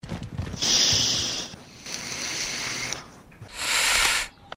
inhale_7N2btFU.mp3